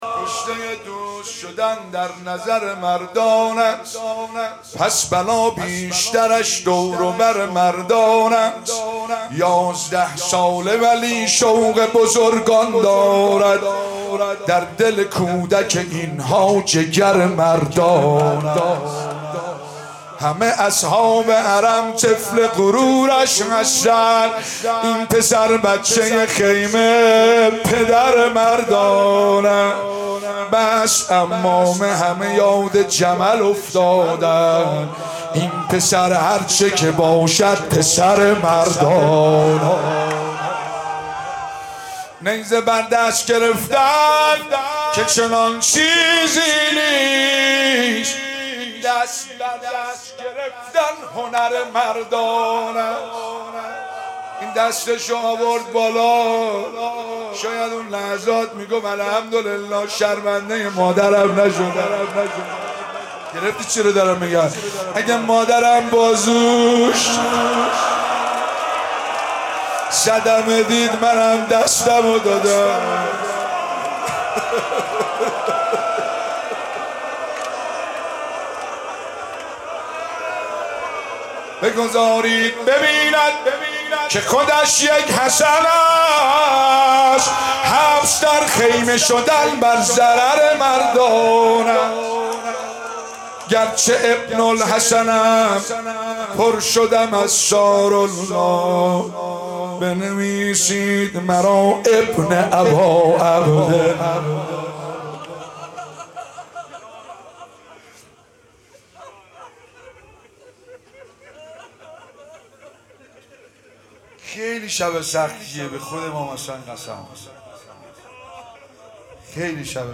شب پنجم محرم 96 - هیئت فاطمیون - روضه